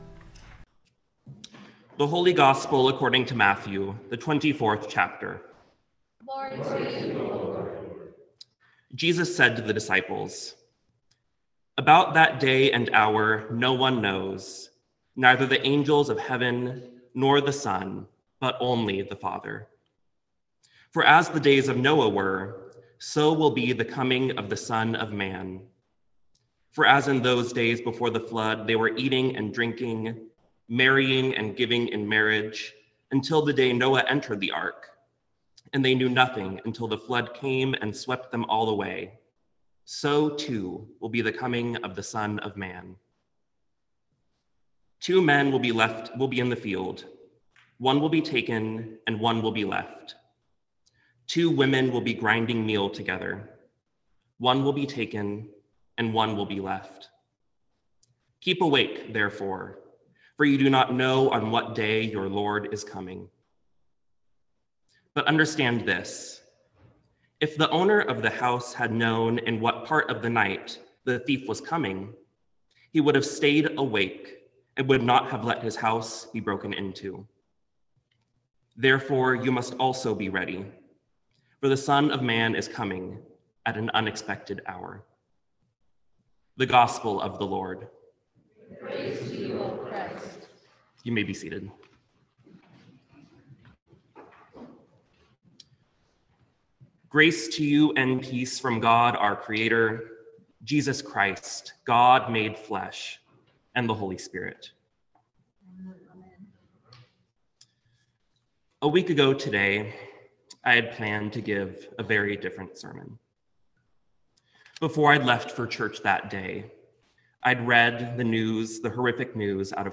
Sermon for the Third Sunday of Advent 2022